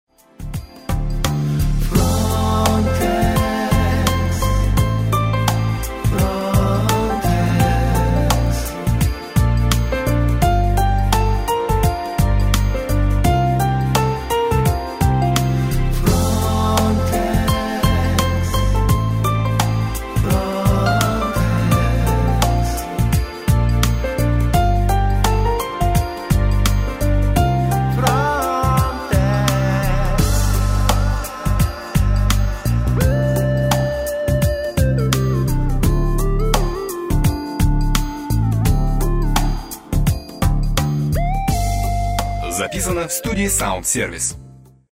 Ниже приведены несколько примеров продакт плейсмент, созданных в студии звукозаписи «СаундСервис»: